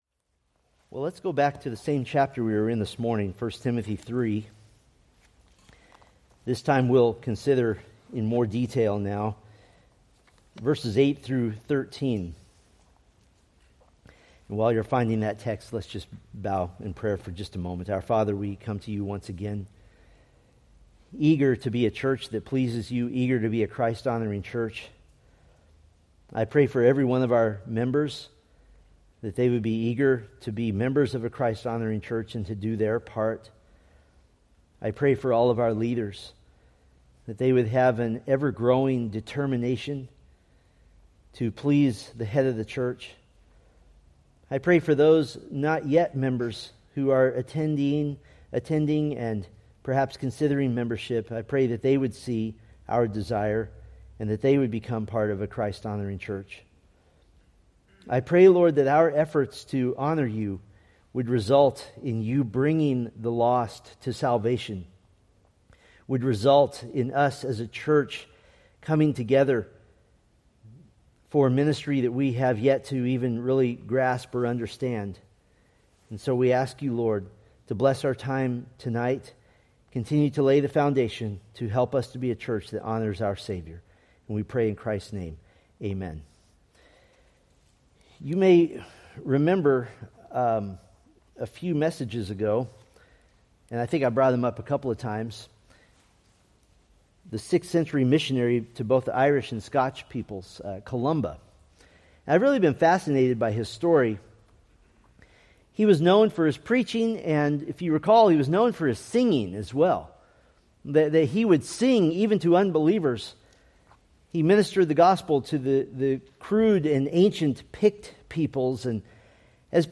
Titus Sermon Series